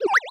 Water2.wav